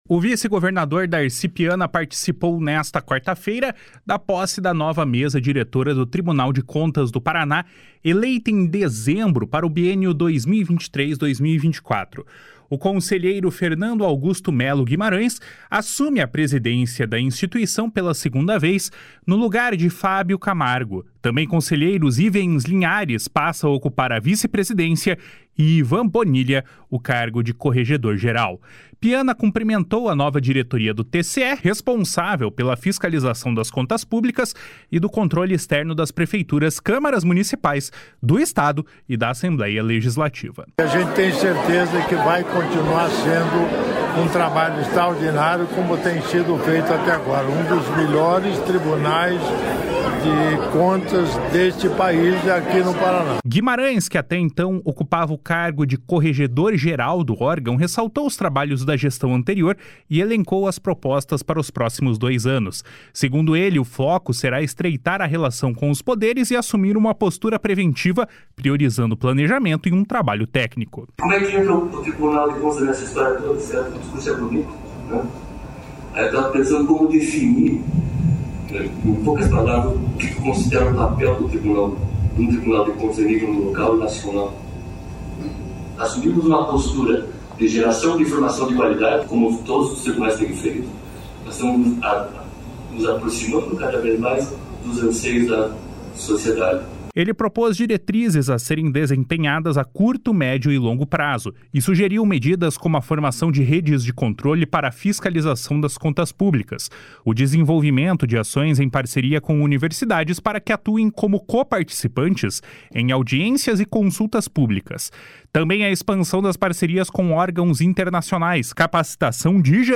// SONORA DARCI PIANA //
Segundo ele, o foco será estreitar a relação com os poderes e assumir uma postura preventiva, priorizando planejamento e um trabalho técnico. // SONORA FERNANDO AUGUSTO MELLO GUIMARÃES //